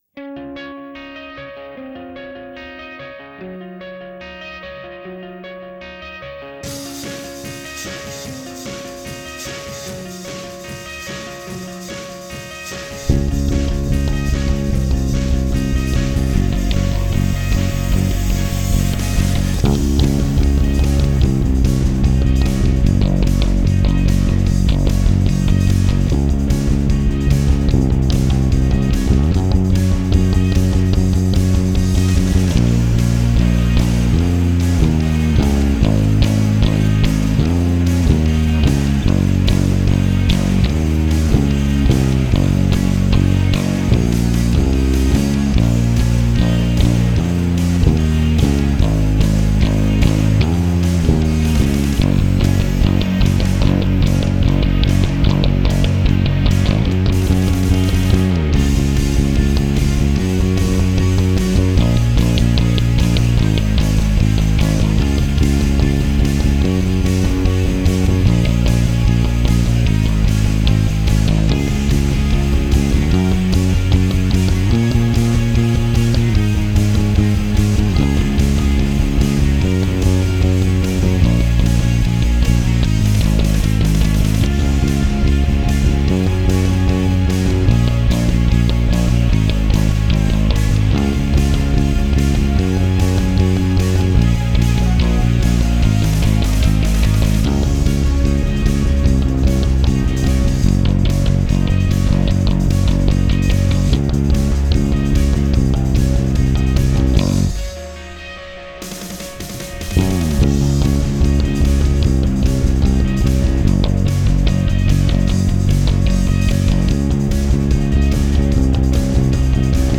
instrumental
Cover / Bass only